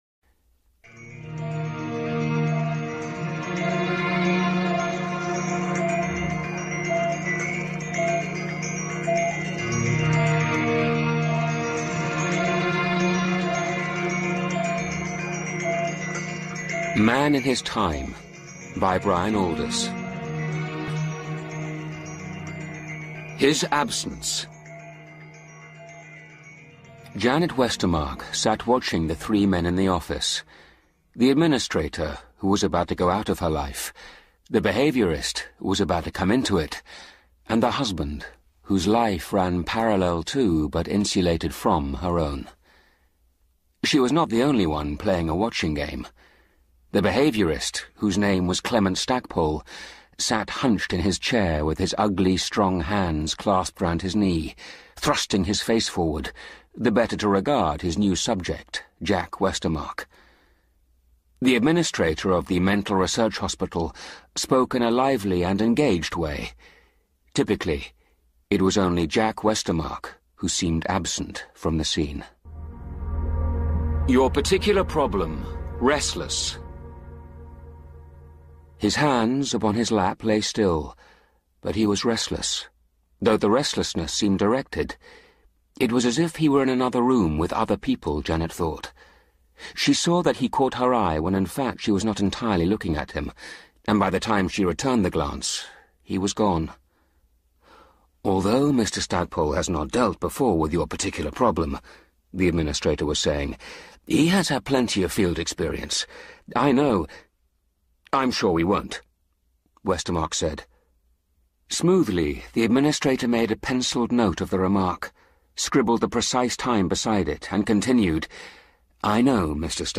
Man In His Time - A BBC Radio Sci-fi Audiobook